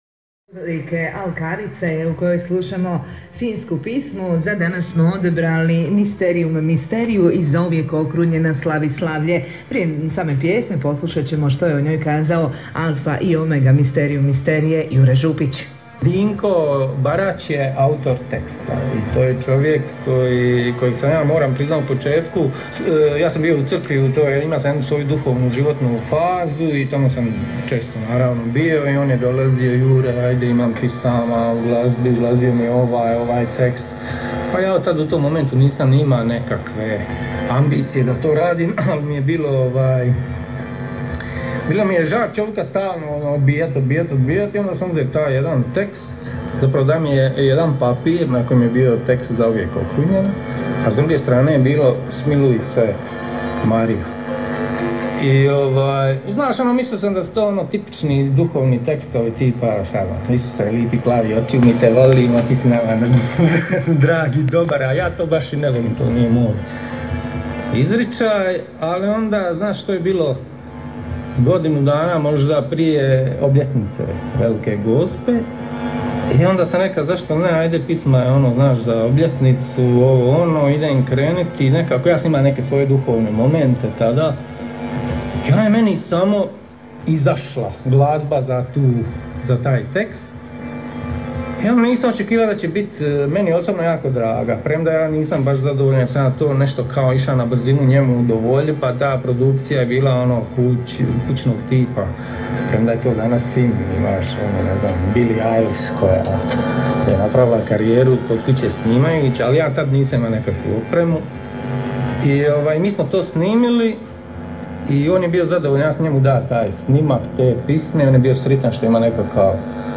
interview 06.08.2020. hit radio